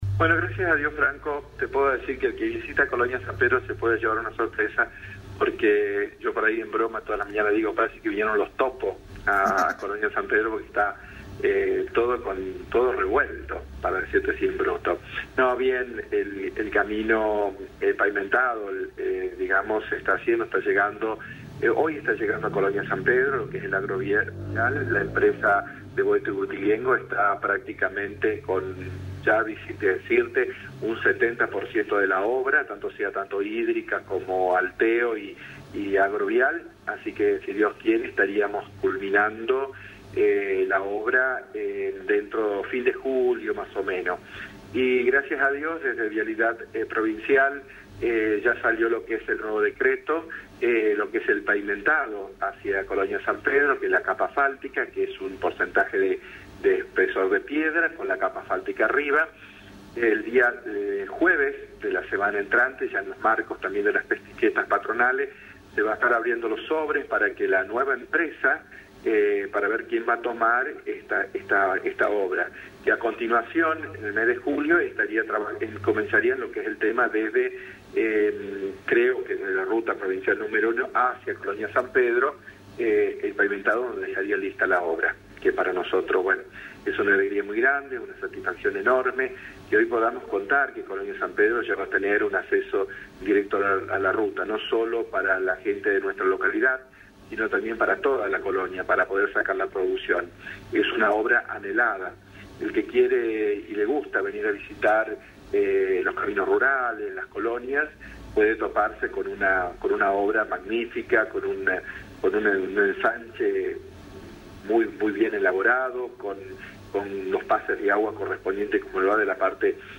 dialogó con LA RADIO 102.9 sobre el programa de actividades.